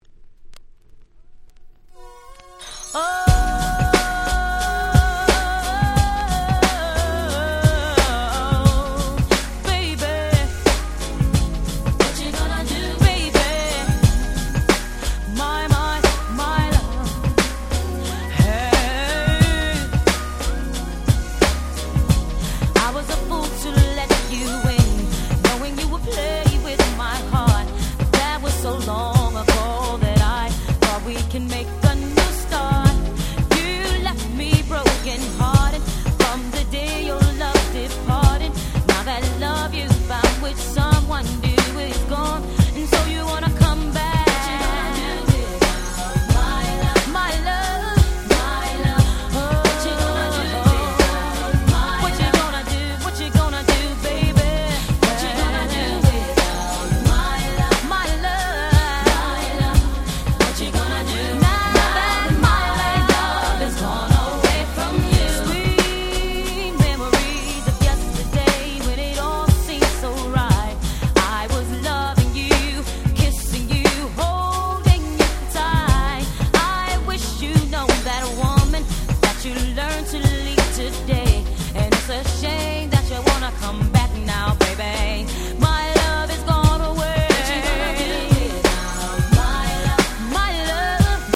94' Smash Hit R&B !!